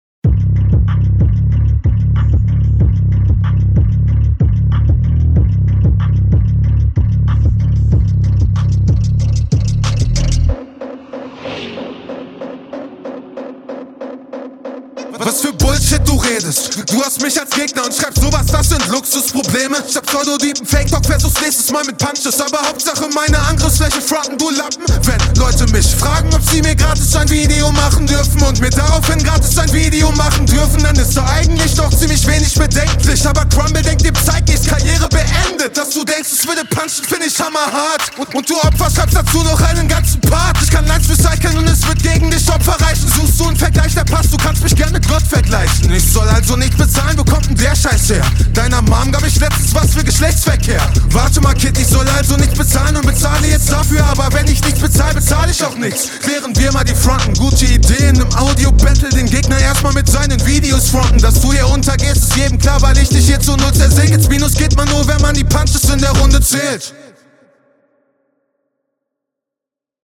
Flow geht diesmal eher nach vorne. Ist vermutlich eher dein Beat.